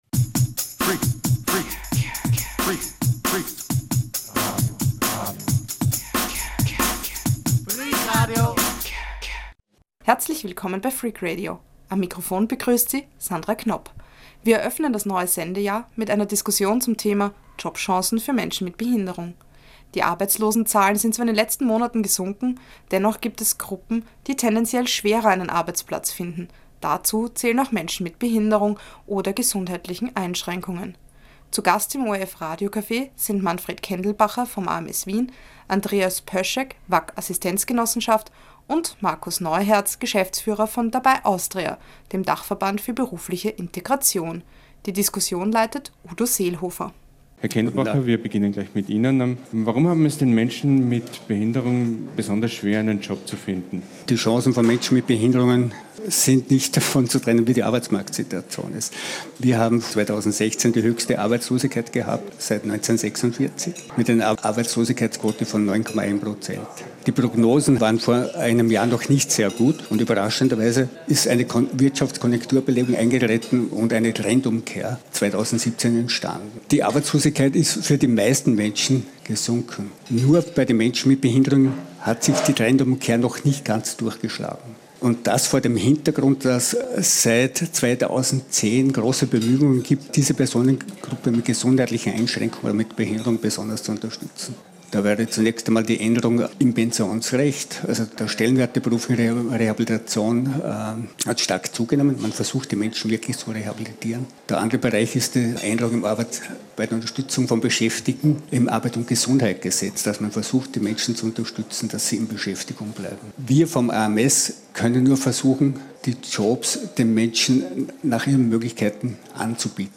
Hat die Lockerung des Kündigungsschutzes die Jobchancen tatsächlich verbessert? Wir diskutieren mit Experten und Betroffenen.